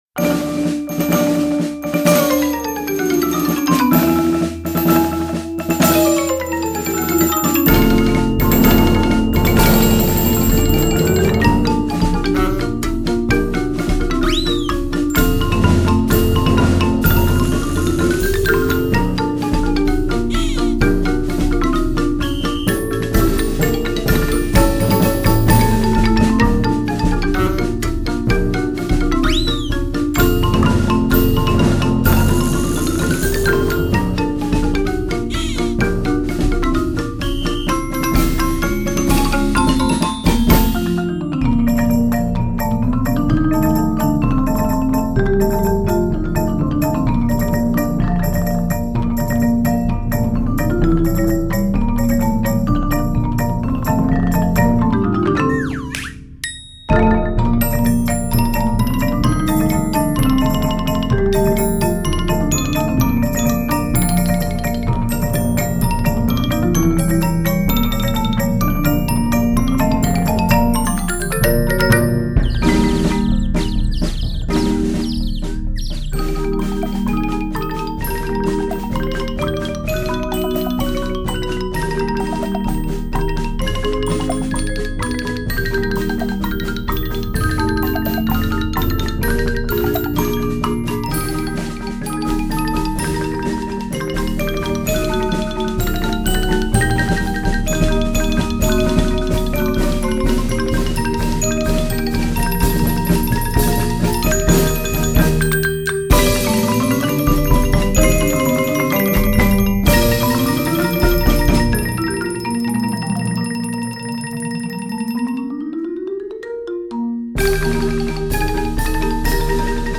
Voicing: 12 Percussion